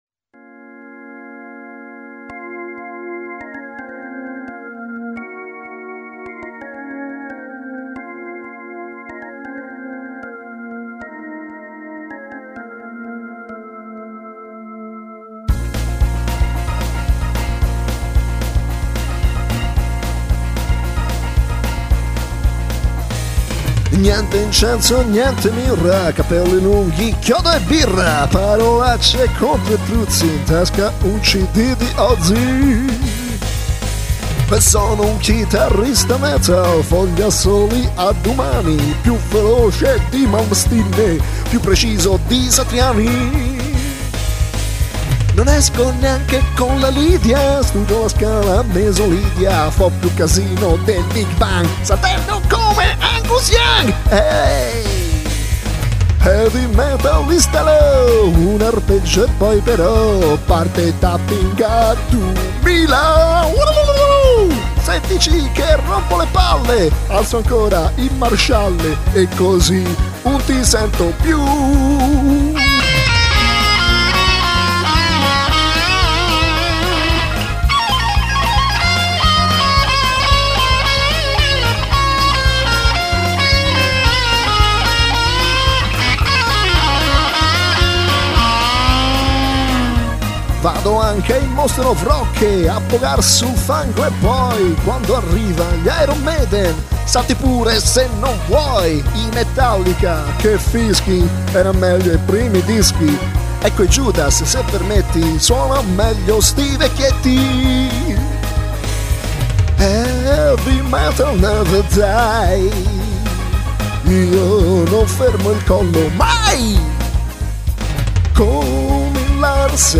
chitarra elettrica